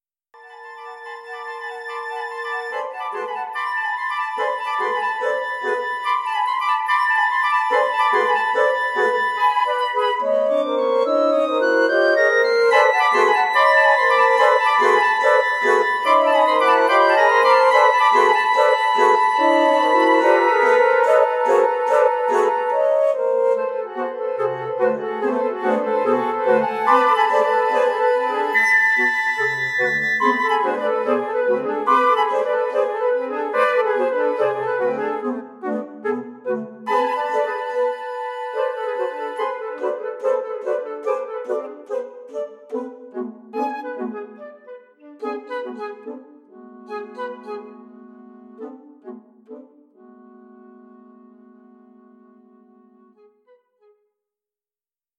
Alle Hör-und Notenbeispiele sind mit dem Notensatzprogramm Sibelius 8.0 generiert worden.
für Pikkolo, sechs Flöten, zwei Altflöten und zwei Bassflöten (2023)
Hörbeispiel Partiturauszug Largo-Agitato